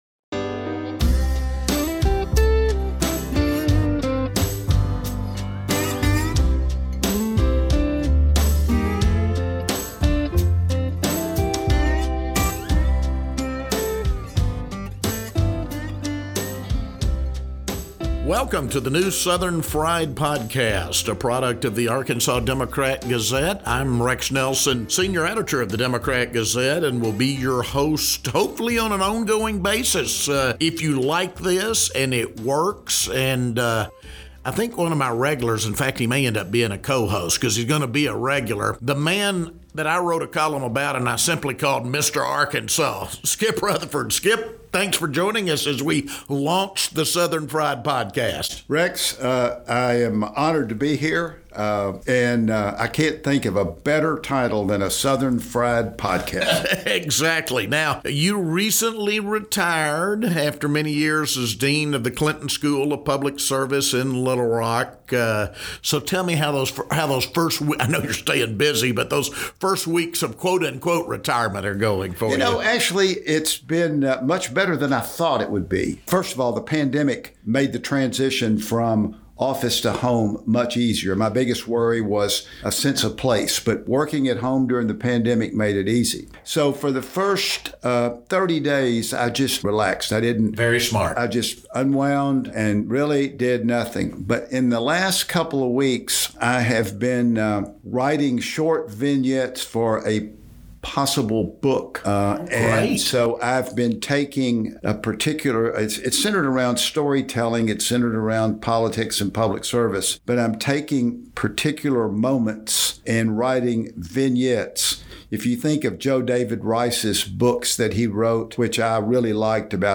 The two friends discuss the future of Little Rock and how Arkansas is changing, as well as a few plans for future episodes.